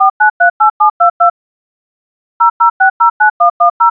dtmf cache.wav